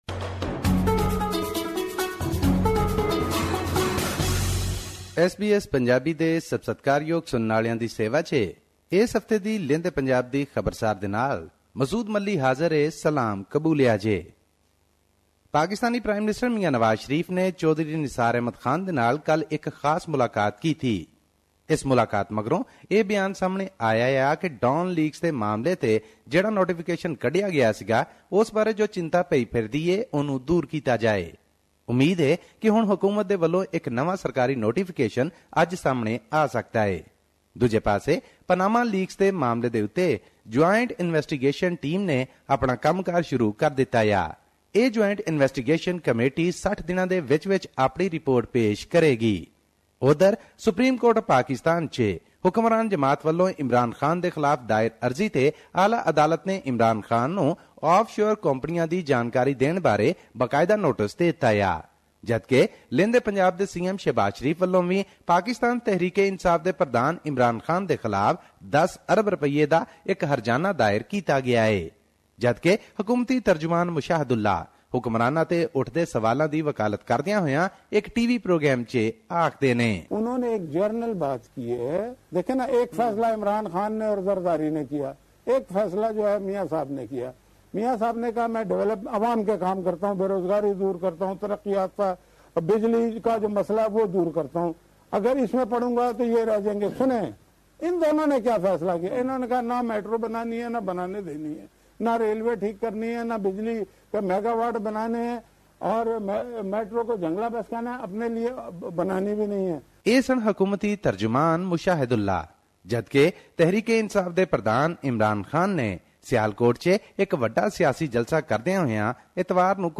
His report was presented on SBS Punjabi program on Tuesday, May 09 2017, which touched upon issues of Punjabi and national significance in Pakistan.